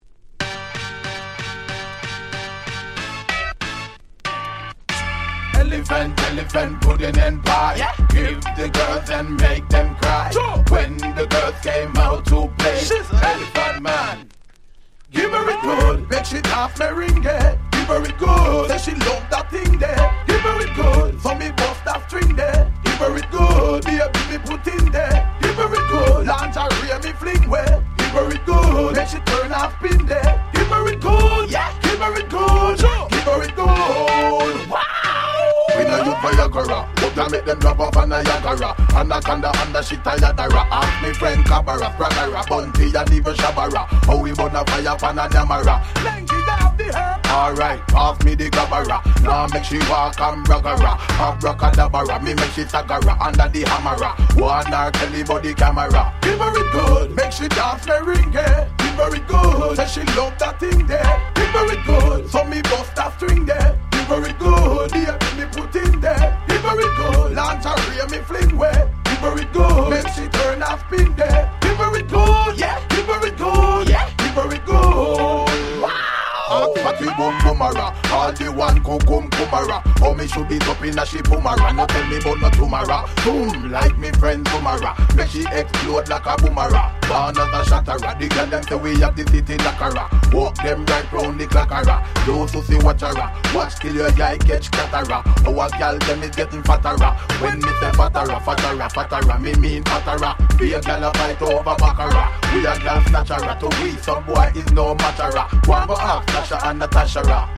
A面はHip Hop Beat vs Reggae DJ
Dancehall Reggae